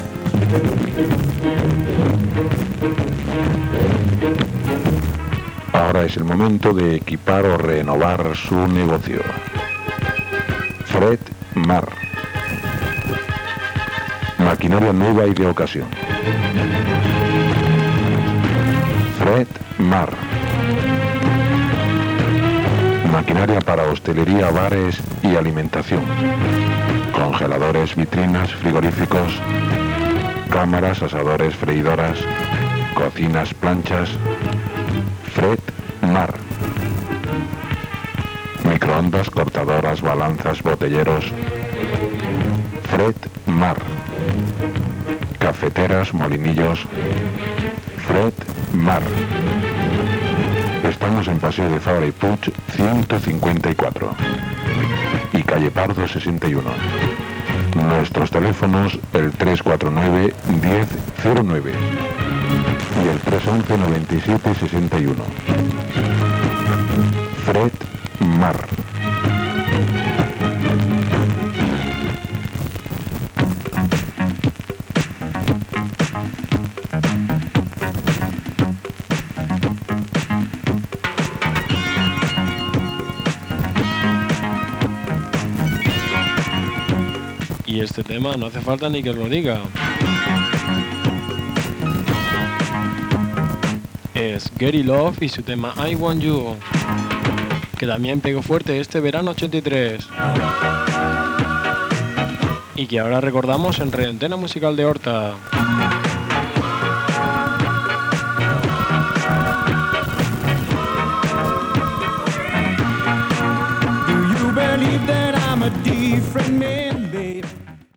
4d3130eb33a5e565d881e19cc339fb52b254a76f.mp3 Títol Ràdio Antena Musical d'Horta Emissora Ràdio Antena Musical d'Horta Titularitat Tercer sector Tercer sector Musical Descripció Publicitat i tema musical.